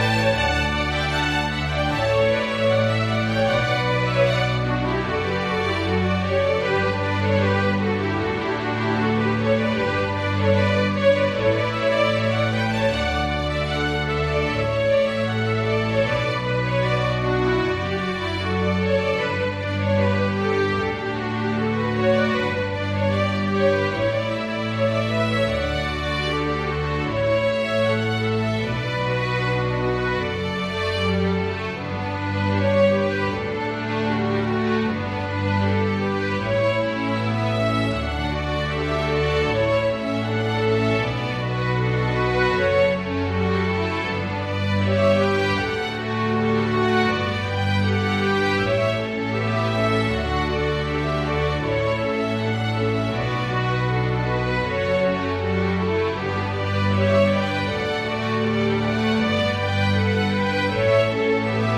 Pachelbel-Canon-in-D.mp3